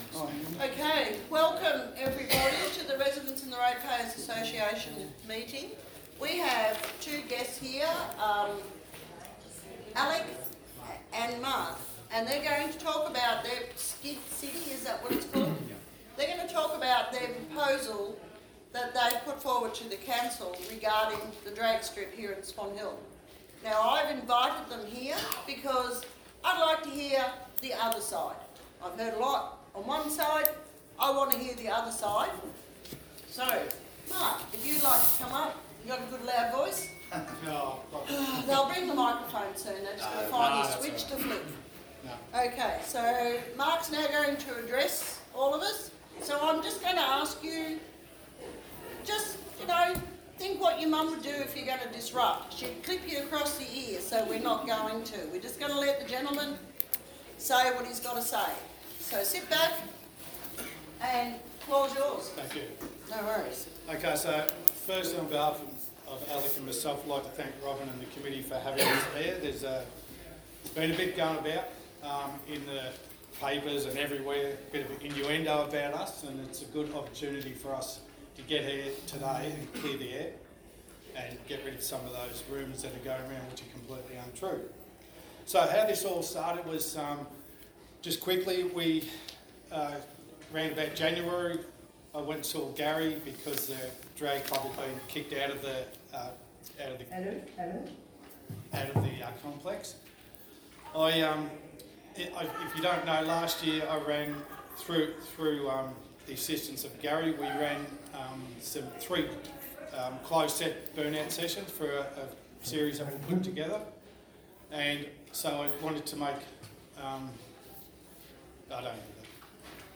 RRA-September-meeting.mp3